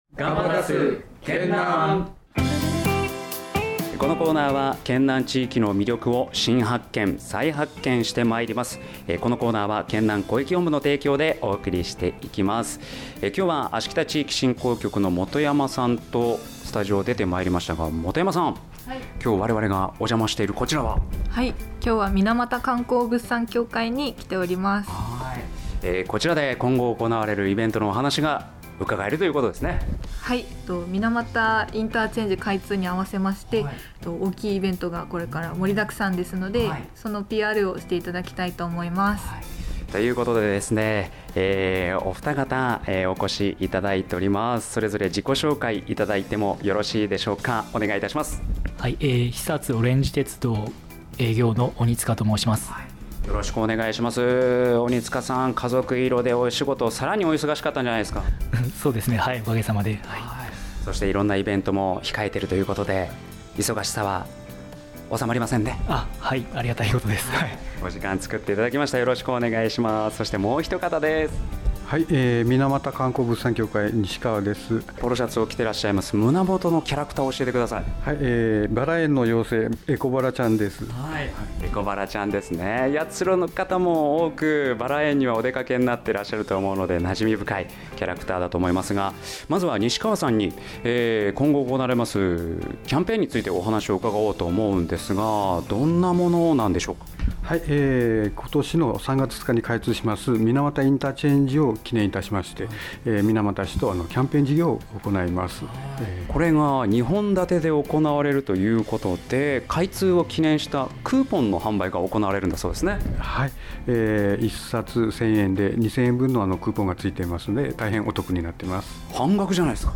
県南地域における県政情報を始めとしたイベント等の様々な情報を皆様へ提供するため、「エフエムやつしろ」でのラジオ放送を行っております。